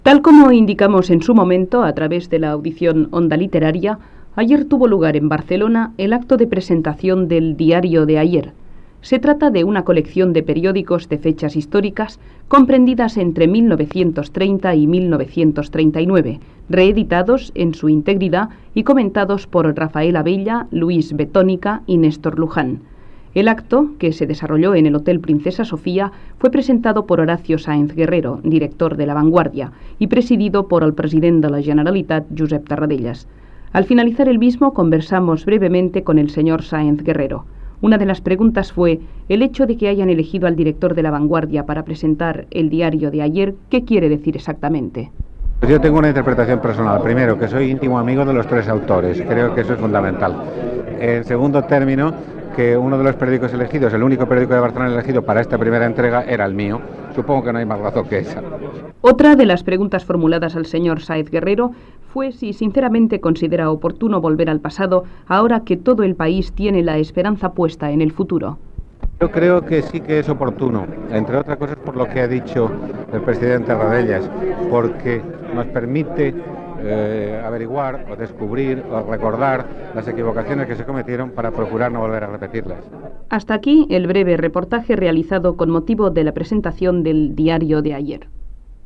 Informació de la presentació a Barcelona de "Diario de ayer", amb declaracions del periodista Horacio Sáenz Guerrero
Informatiu